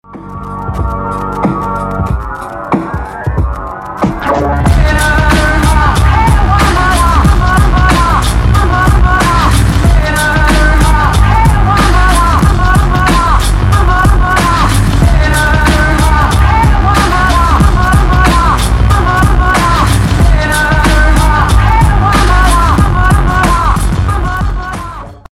хип-хоп
trap